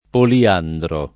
poliandro [ poli- # ndro ] agg.